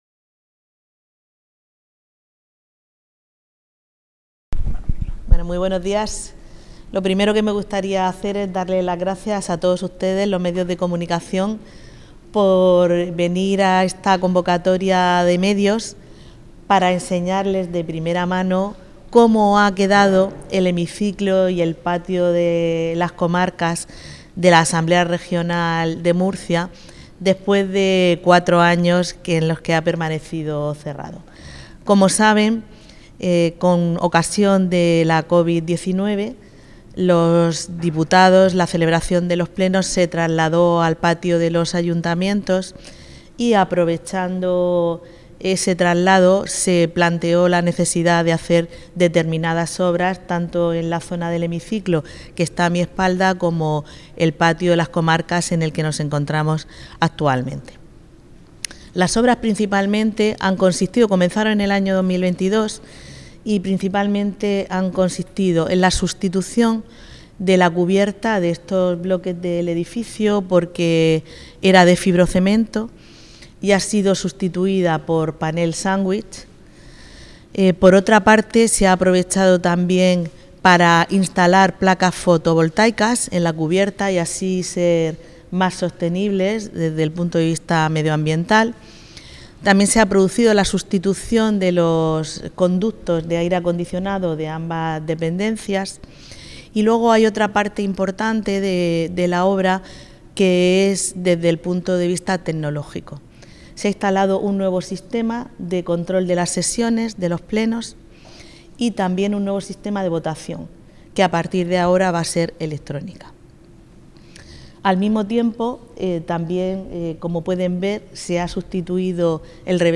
• Declaraciones de la presidenta de la Asamblea Regional, Visitación Martínez